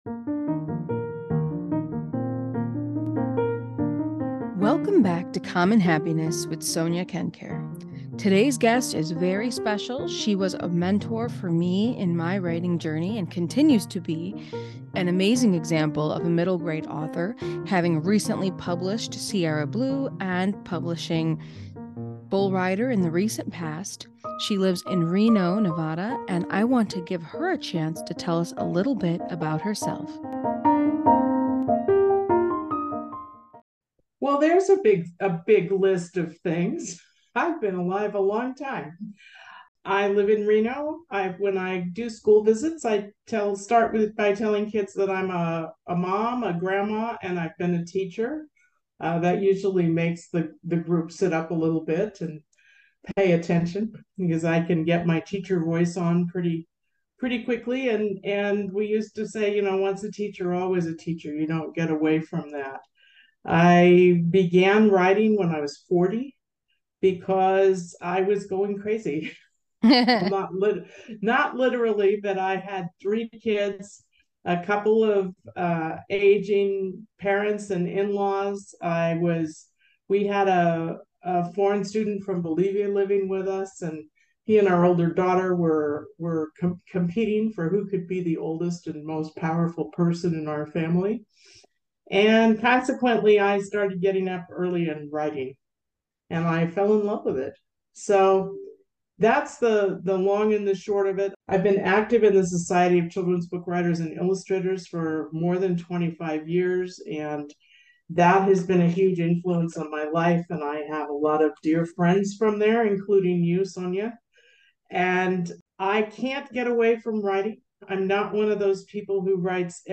Podcast Interview about SIERRA BLUE, writing, and happiness
This is a 36 minute interview.